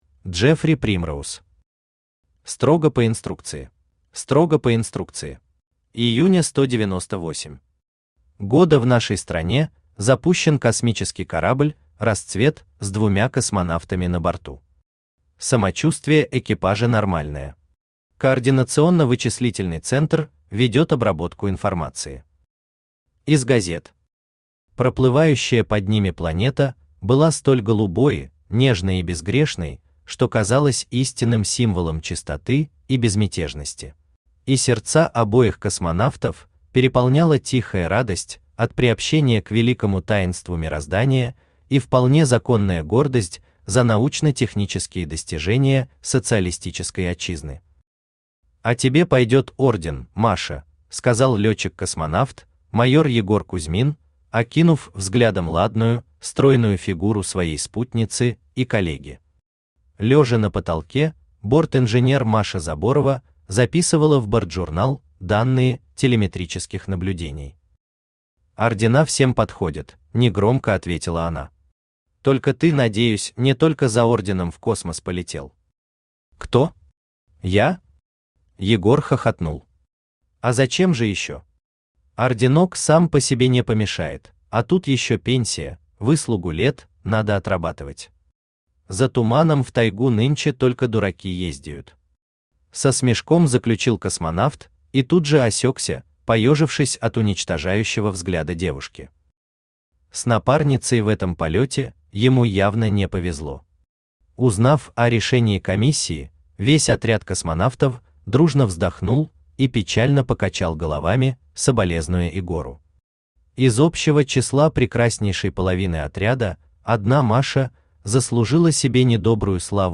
Аудиокнига Строго по инструкции | Библиотека аудиокниг
Aудиокнига Строго по инструкции Автор Джеффри Примроуз Читает аудиокнигу Авточтец ЛитРес.